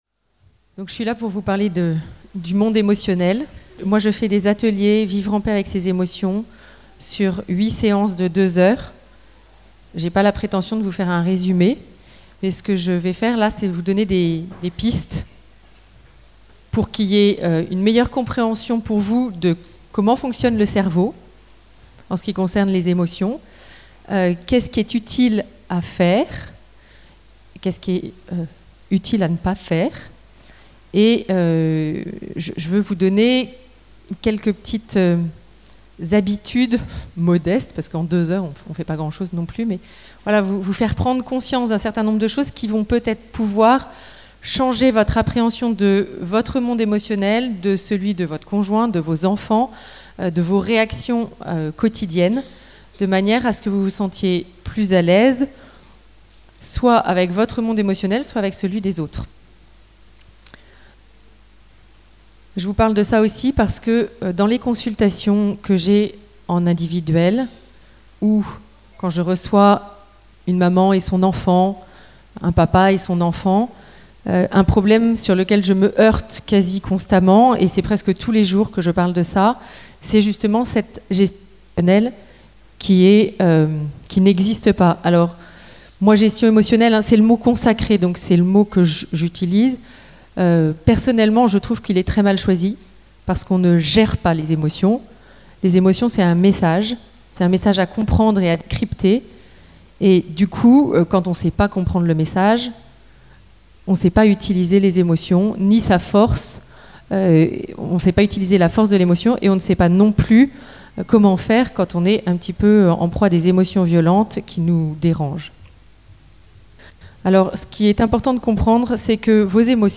CD Petites Conférences Spirituelles, CD Croissance humaine
Enregistré lors du Chapitre des familles des franciscains de Cholet en mai 2015.